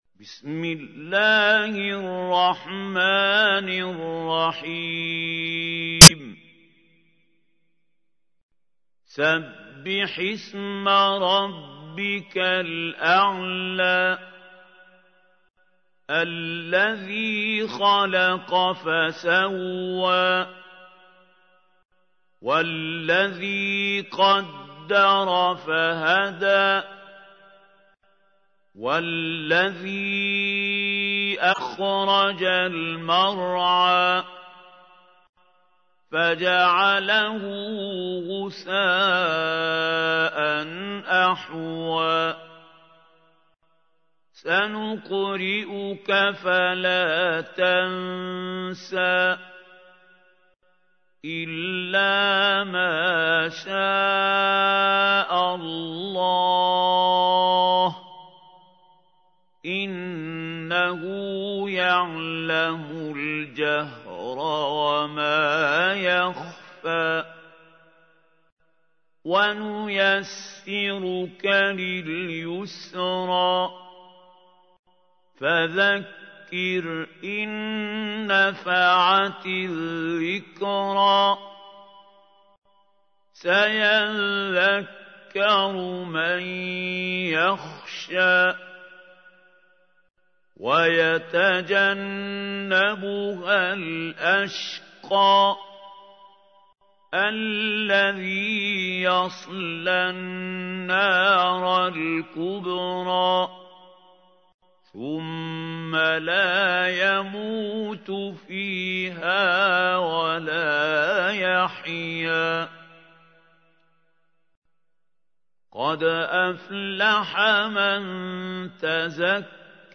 تحميل : 87. سورة الأعلى / القارئ محمود خليل الحصري / القرآن الكريم / موقع يا حسين